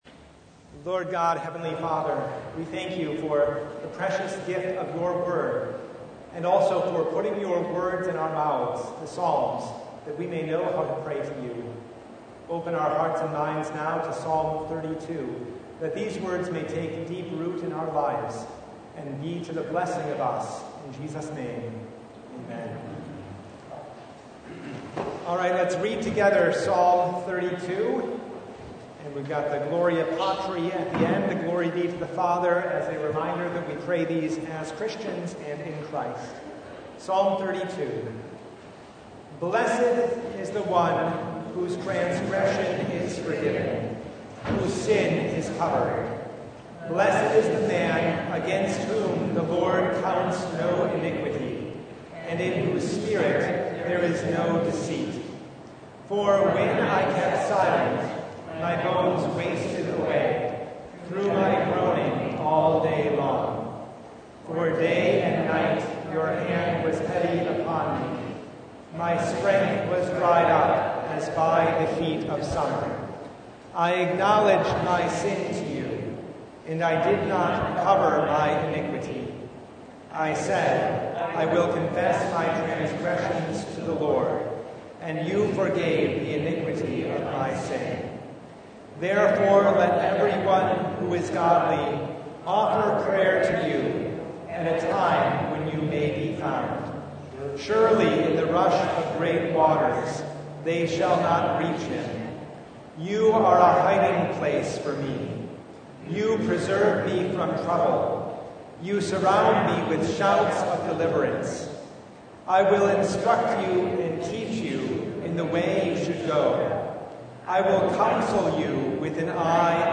Psalm 32 Service Type: Bible Study Blessed is the one whose transgression is forgiven